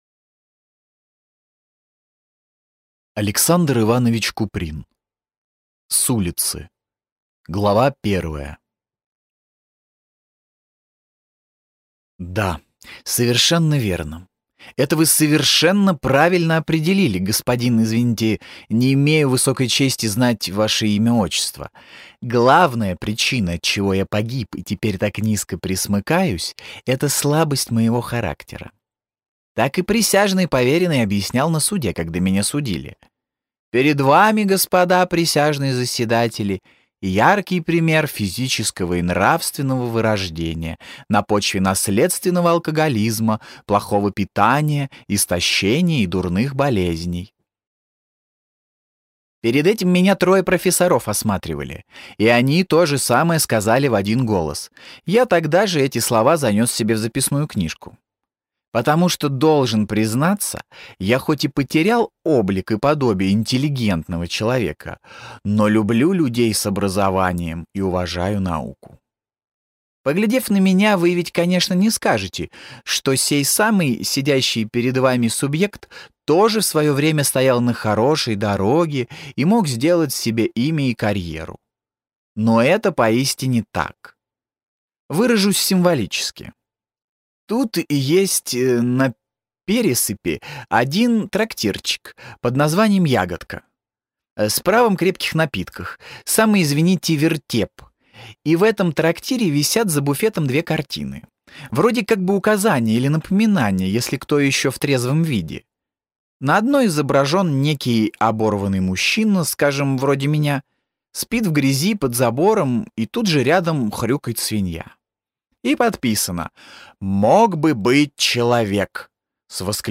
Аудиокнига С улицы | Библиотека аудиокниг
Прослушать и бесплатно скачать фрагмент аудиокниги